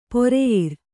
♪ poreyir